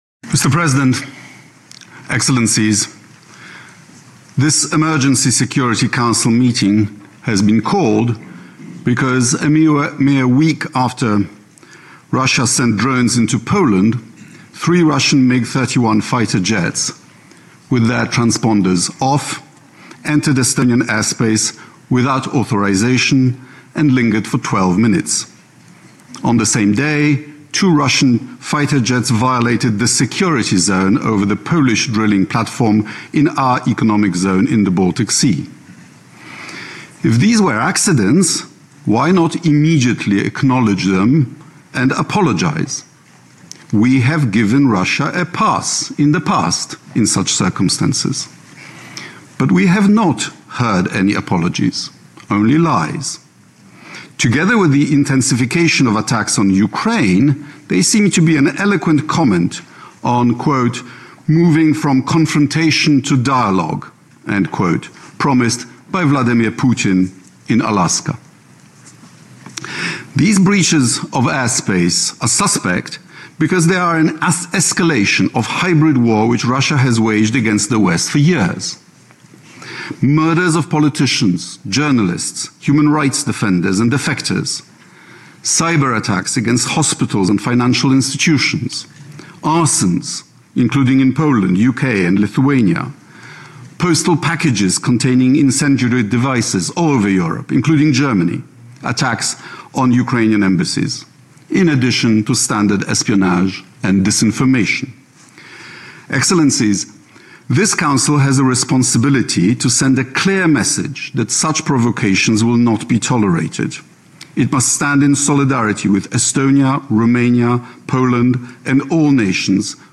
Remarks at an Emergency Meeting of the UN Security Council on Russian Incursion into NATO Airspace
delivered 22 September 2025, United Nations Headquarters, New York, NY
Audio Note: AR-XE = American Rhetoric Extreme Enhancement